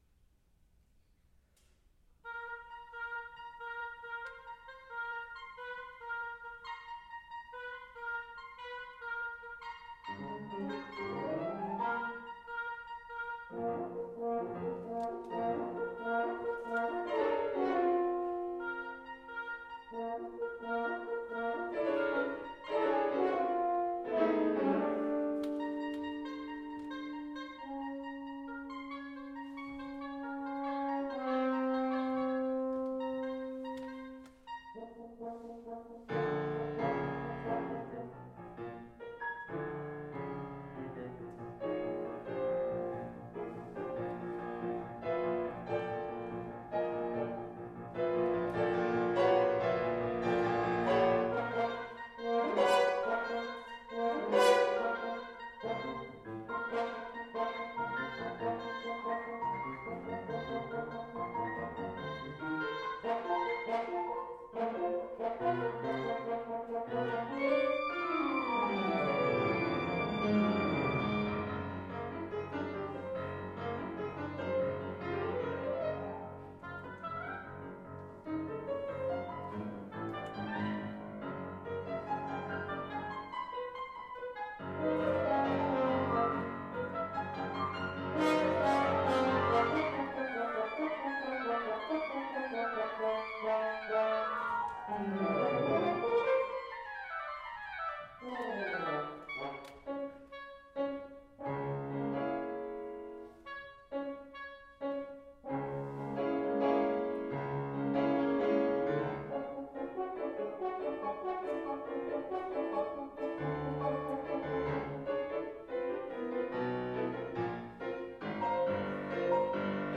for Oboe, Horn & Piano
Premiered on february 2016 in Aulnay, France